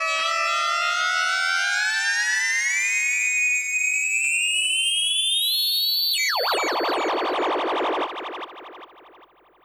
45 SYNTH 3-R.wav